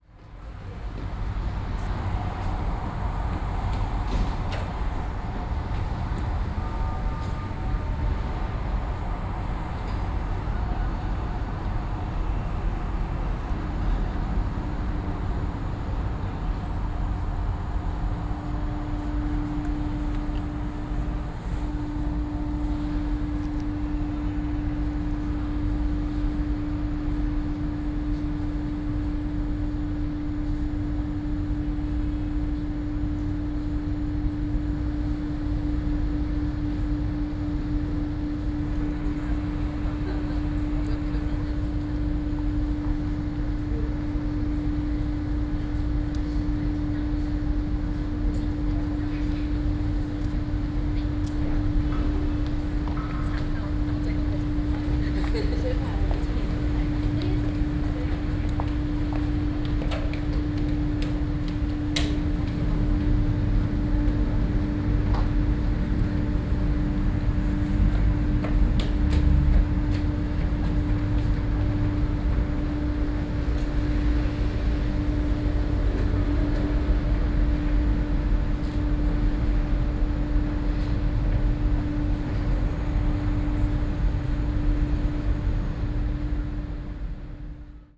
ambient-traffic.wav